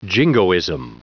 Prononciation du mot jingoism en anglais (fichier audio)
Prononciation du mot : jingoism